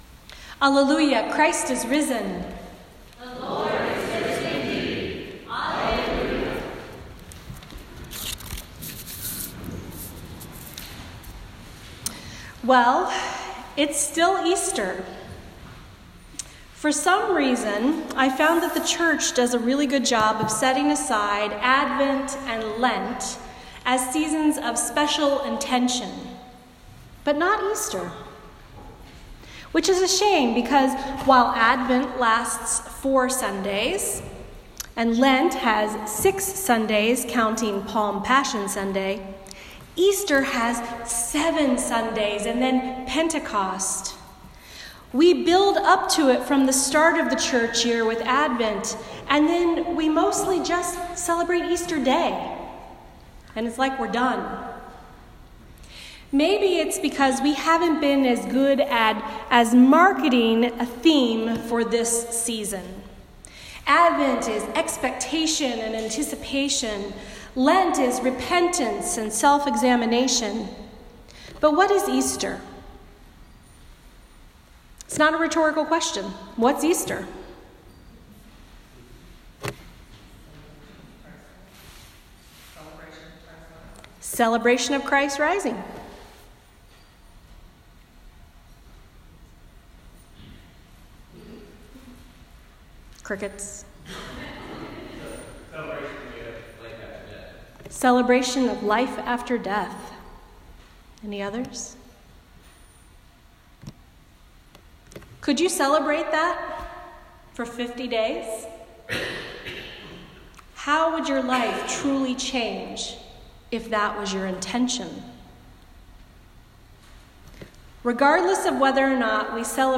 A Sermon for the Second Sunday after Easter